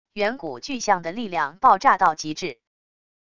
远古巨象的力量爆炸到极致wav音频